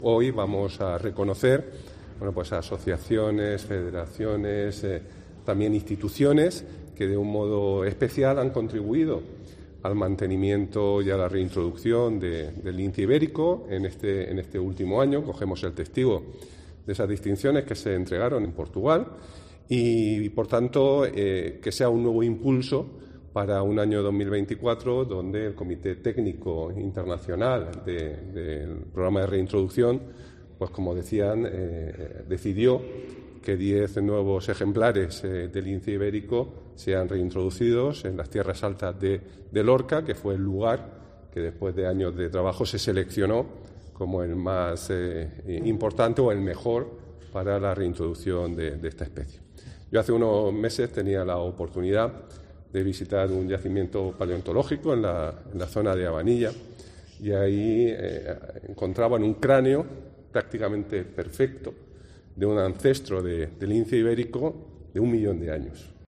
El consejero de Medio Ambiente, Universidades, Investigación y Mar Menor, Juan María Vázquez